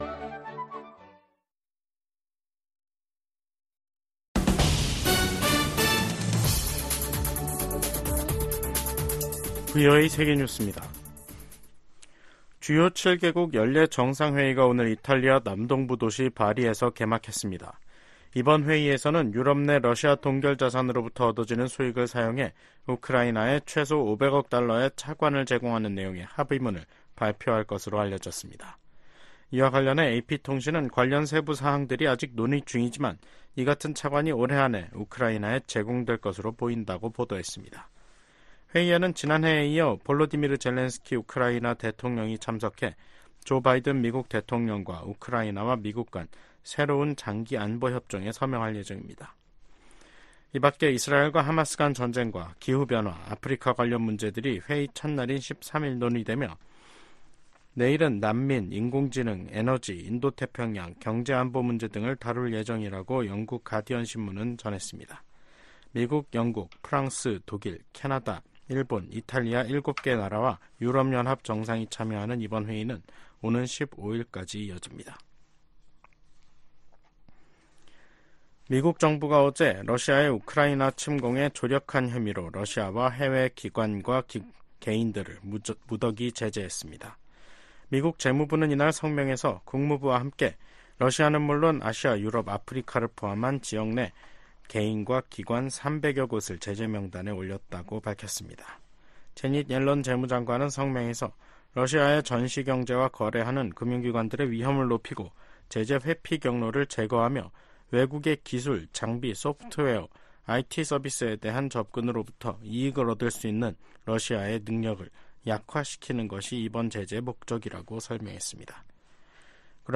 VOA 한국어 간판 뉴스 프로그램 '뉴스 투데이', 2024년 6월 13일 3부 방송입니다. 유엔 안보리에서 열린 북한 인권공개 회의에서 미국과 한국, 일본 등은 북한 인권 유린이 불법적인 무기 개발과 밀접한 관계에 있다고 지적했습니다. 미한일 등 50여개국과 유럽연합(EU)이 뉴욕 유엔본부에서 개선될 조짐이 없는 북한의 인권 상황에 대해 우려를 표명했습니다.